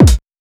Wu-RZA-Kick 70.wav